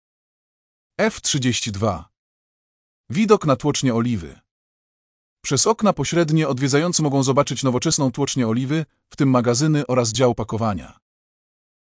Wycieczka z przewodnikiem audio